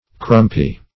crumpy - definition of crumpy - synonyms, pronunciation, spelling from Free Dictionary Search Result for " crumpy" : The Collaborative International Dictionary of English v.0.48: Crumpy \Crump"y\ (kr[u^]mp"[y^]), a. Brittle; crisp.